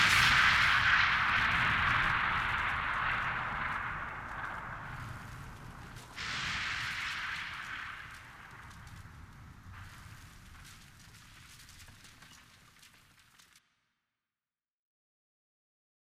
Dro White Noise.mp3